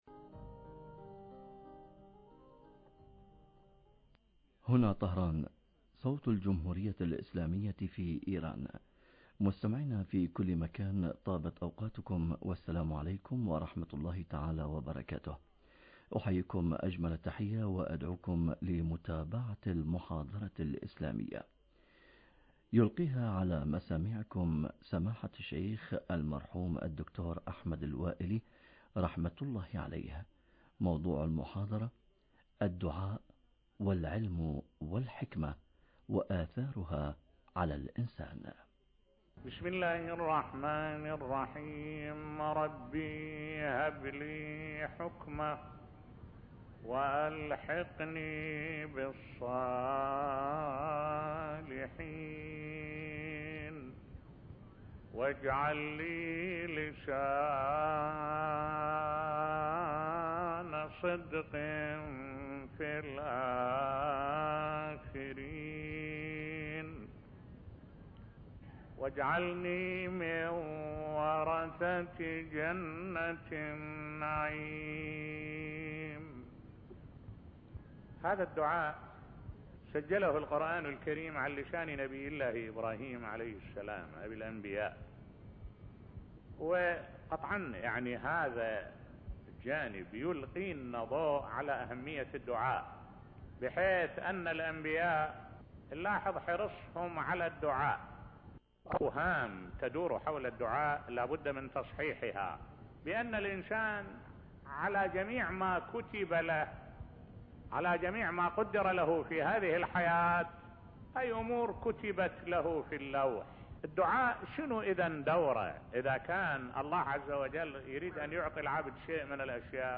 محاضرات أخرى